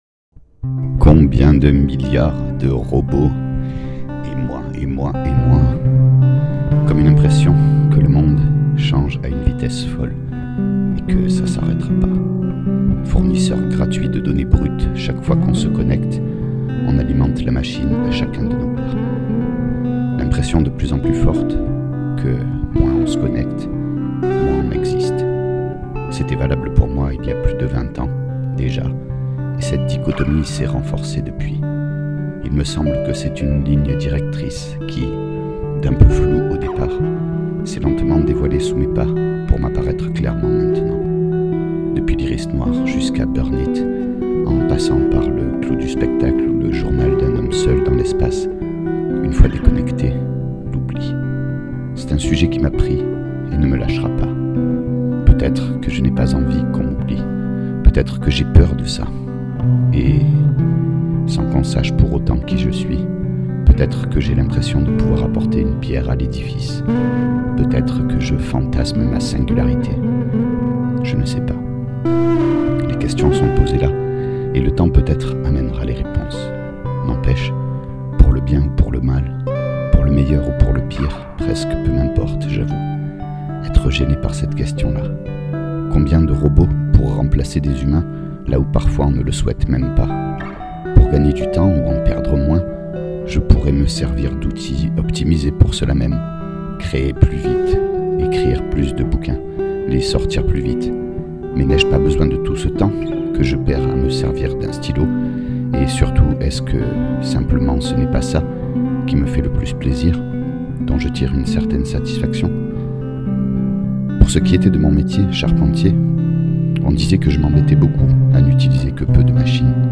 Le principe est tout simple : Un texte, comme une chronique, rédigé(e) avec un stylo sur un cahier (parce que c’est comme ça que j’écris), lu(e) devant un micro et accompagné(e) d’une improvisation à la guitare.
De plus je ne dispose pas d’un excellent matériel (un vieil ordi, une carte son externe M-Audio, un micro chant et une guitare) et je pense pouvoir améliorer la qualité du son, avec le temps et un peu d’investissement, mais prenez plutôt cet exercice comme une expérimentation de ma part, vous serez moins déçu(e)s. A noter : Les dates correspondent aux phases d’écriture, pas à l’enregistrement de la musique, mise en boîte quand il m’en vient la motivation.